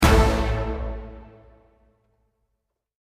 Valorant 4th Kill Sound Button - Free Download & Play
Games Soundboard1,328 views